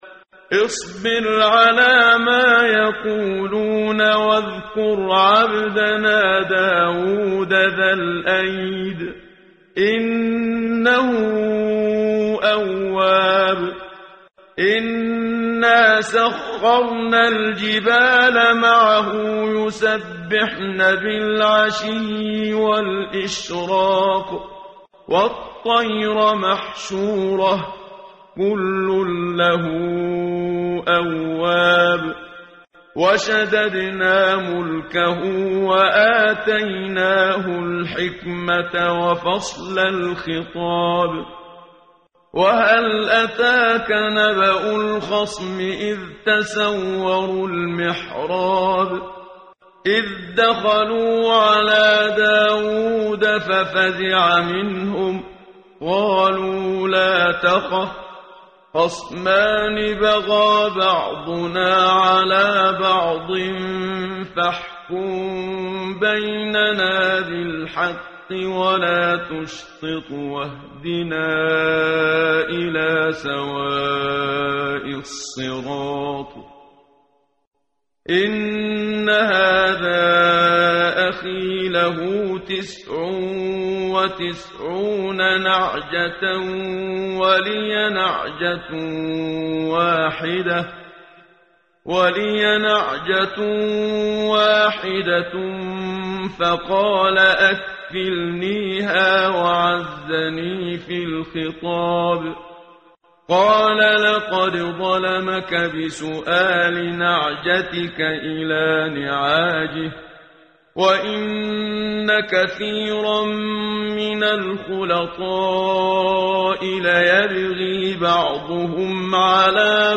قرائت قرآن کریم ، صفحه 454 ، سوره مبارکه «ص» آیه17 تا 26 با صدای استاد صدیق منشاوی.